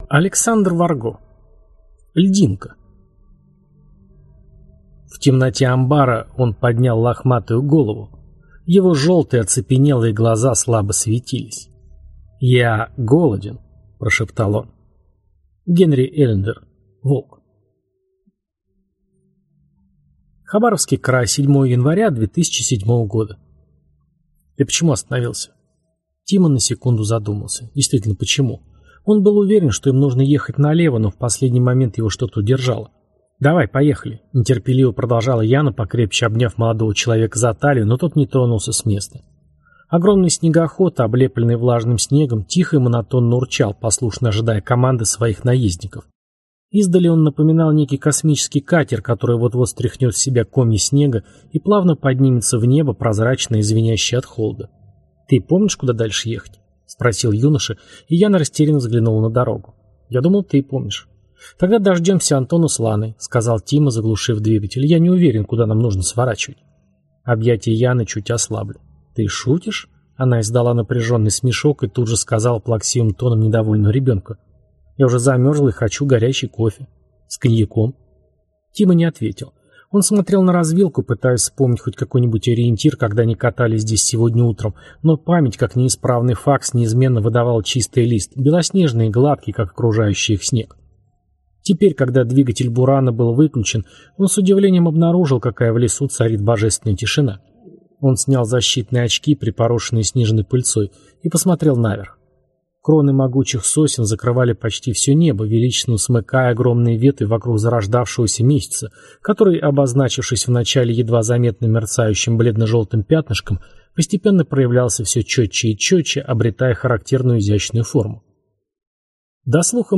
Аудиокнига Льдинка | Библиотека аудиокниг